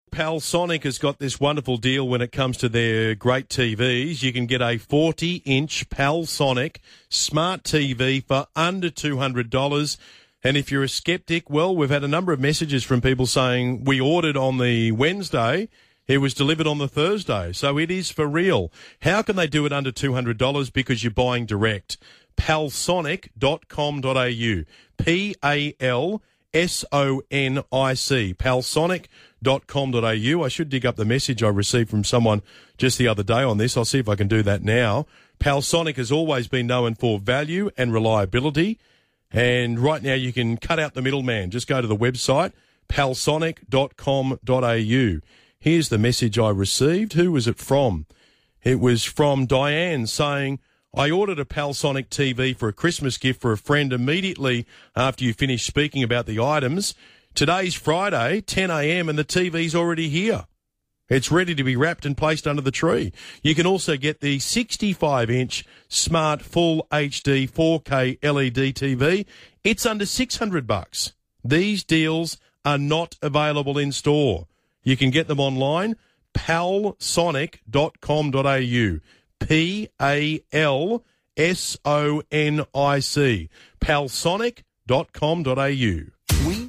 This authentic recommendation from Ben Fordham sold 40 Palsonic TVs straight after the first live read aired on 2GB Breakfast.
2GB-Palsonic-Live-Read-with-Ben-Fordham-No-2.mp3